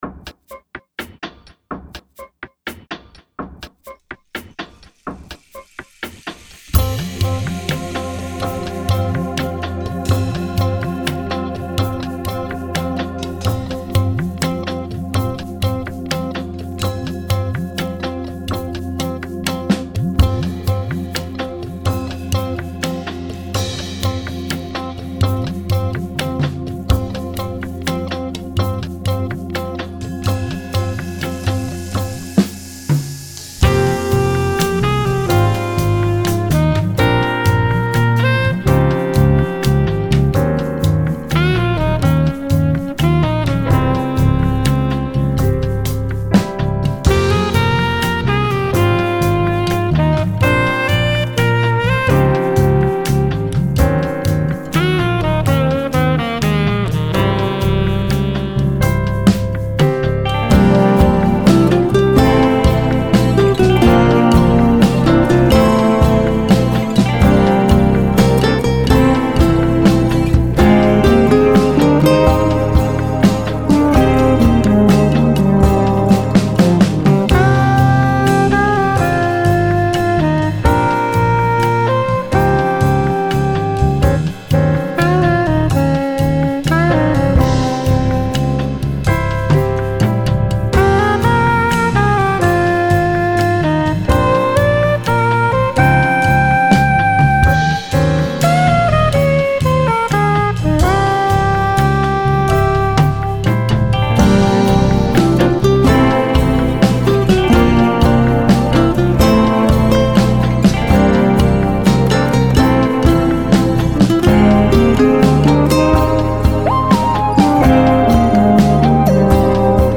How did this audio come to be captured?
Recorded at WCSU Recording Studios A & B (Danbury, CT)